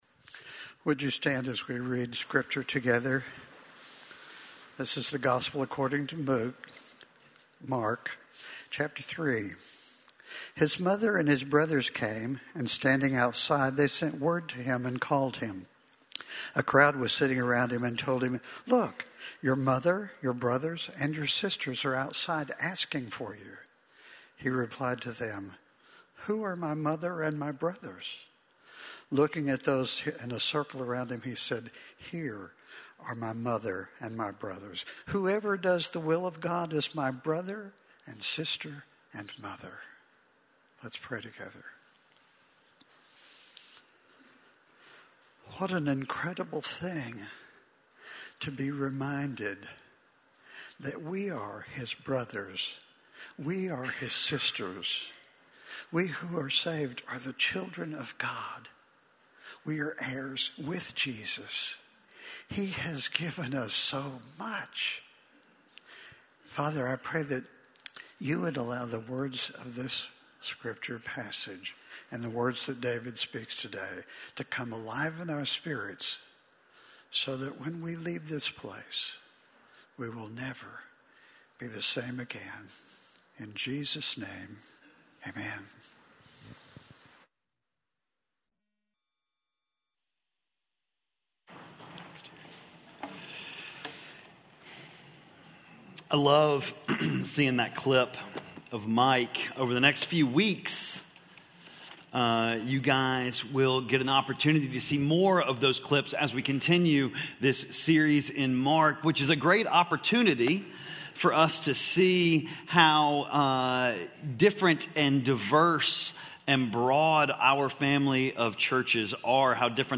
Family - Sermon - Lockeland Springs